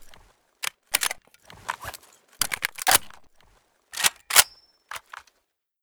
sa58_reload_empty.ogg